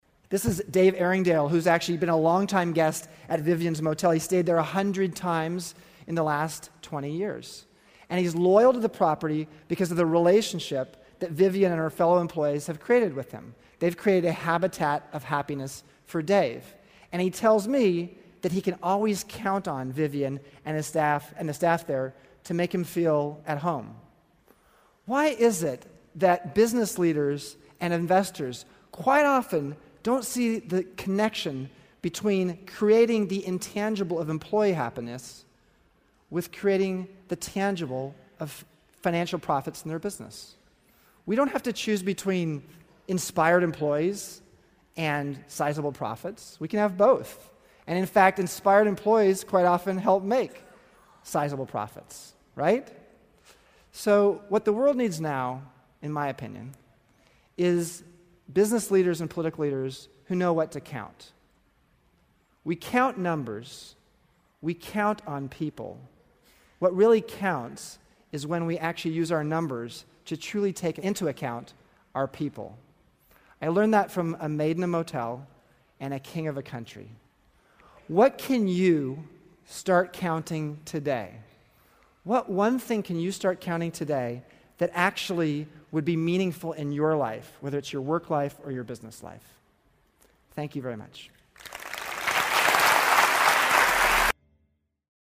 财富精英励志演讲80 :计算让生命有意义的事情(11) 听力文件下载—在线英语听力室